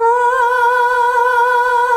AAAAH   B.wav